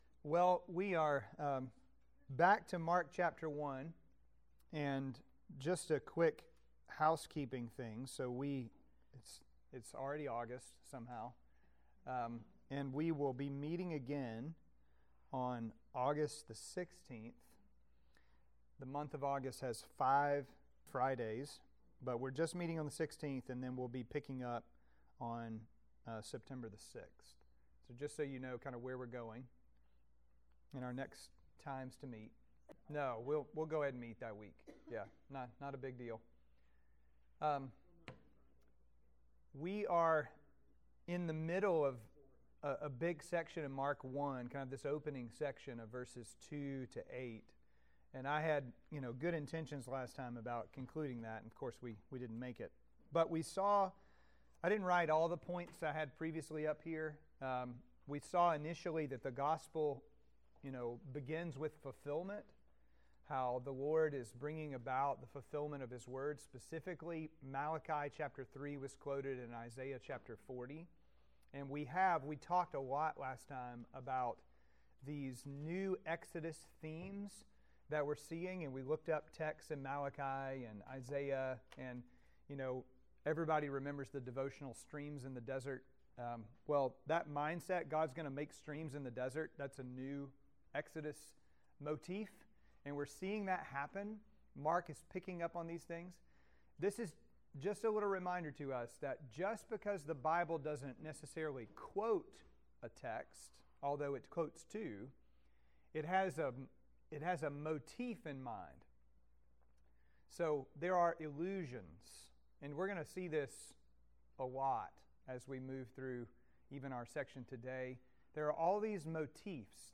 Mark 1:4-8 Sermons & Sunday School Series podcast To give you the best possible experience, this site uses cookies.